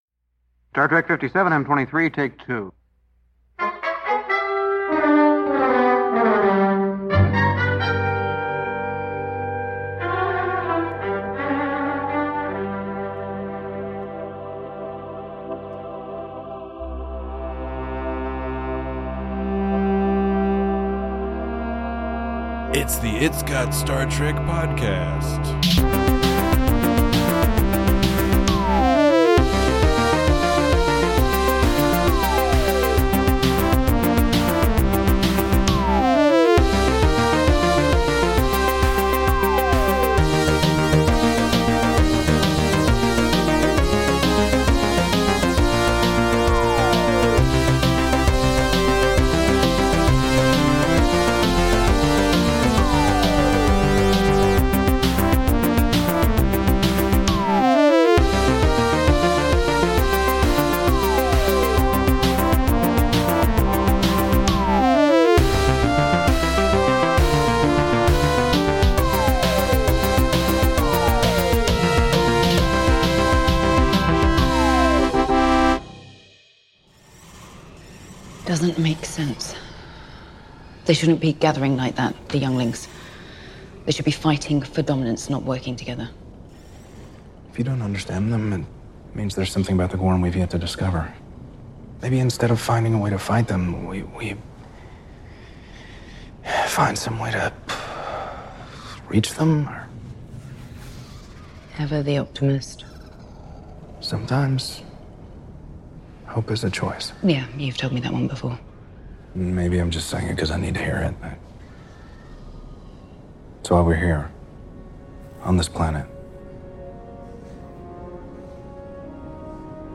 Just when everyone thought they had everything figured out, a bunch of lizards show up and ruin the party. Join your reptilian hosts as they discuss action, drama, gremlins, Jurassic Park, zombie films, monsters, space battles, and much much more about Star Trek: Strange New Worlds' season two finale!